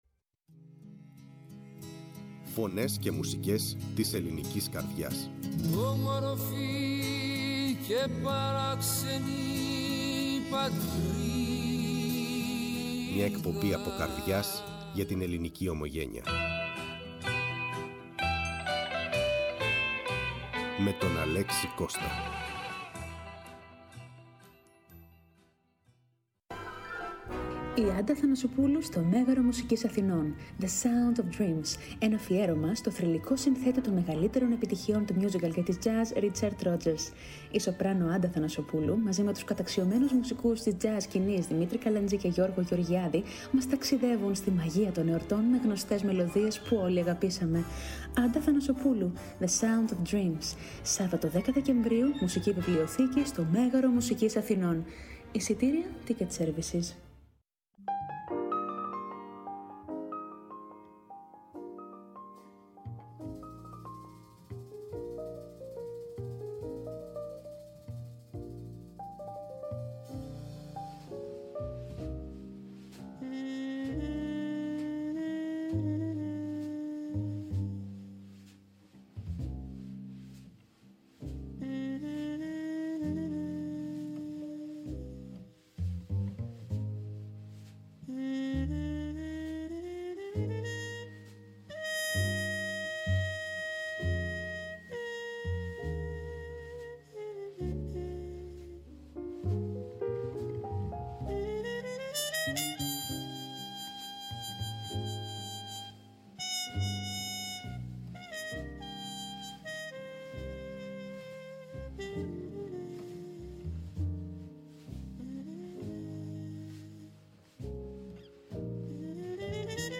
σοπράνο